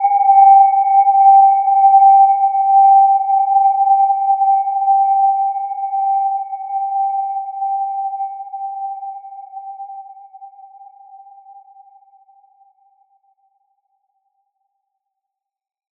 Gentle-Metallic-3-G5-mf.wav